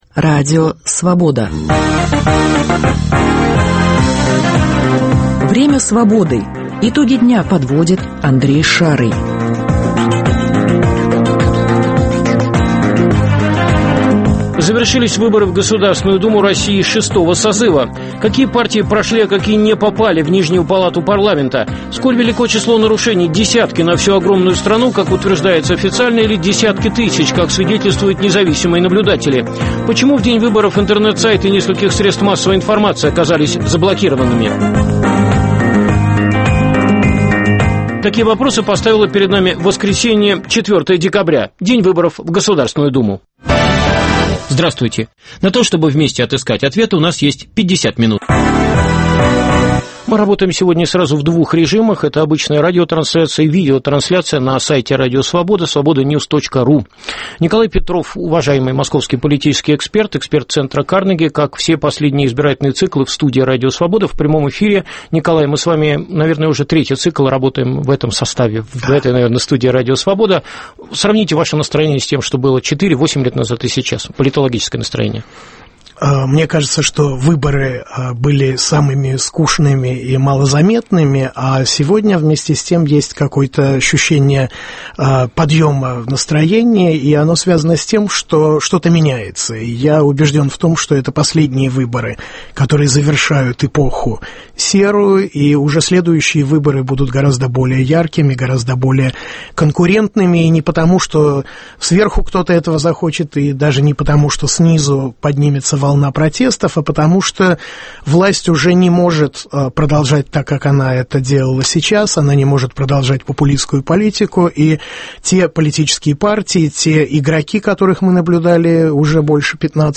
Первые официальные результаты выборов в Государственную Думу России. Репортажи корреспондентов РС из десятков регионов России, из избирательных штабов политических партий. Мнения независимых экспертов о масштабе нарушений во время выборов.